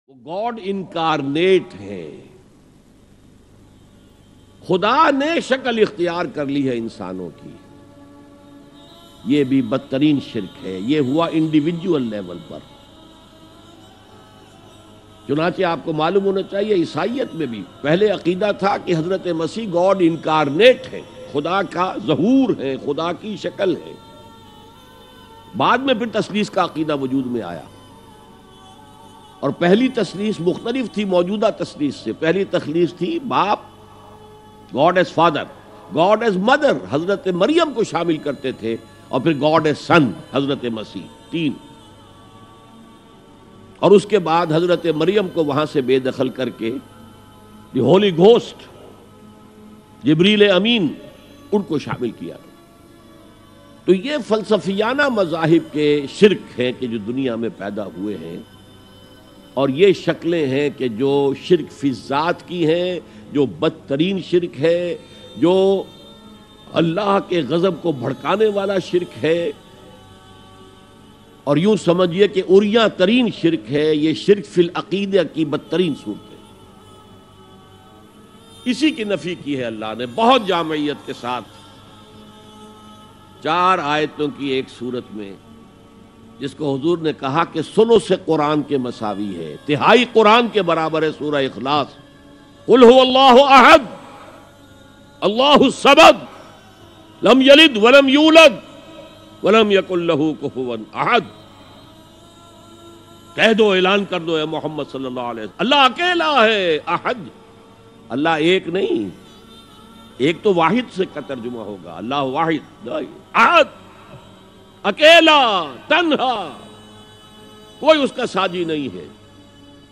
Allah Kaisa Hai Bayan MP3 Download By Israr Ahmad
Dr Israr Ahmed R.A a renowned Islamic scholar.